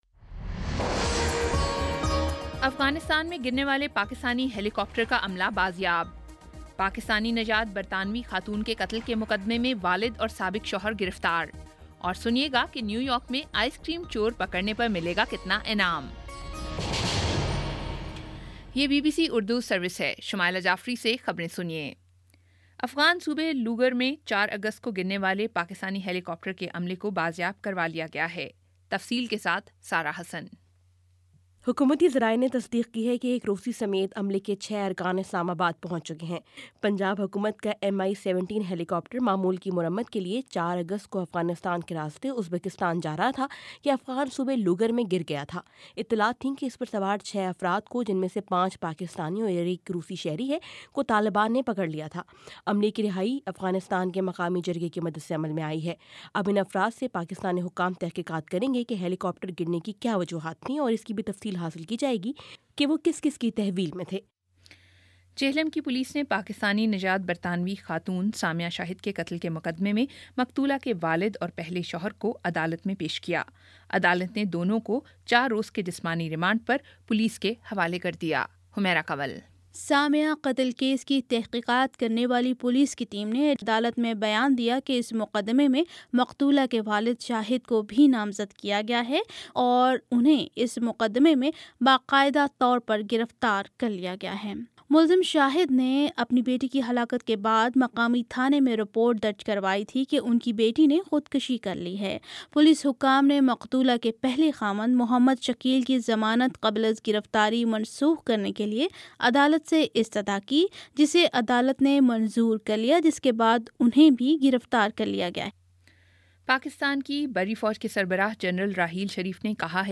اگست 13 : شام پانچ بجے کا نیوز بُلیٹن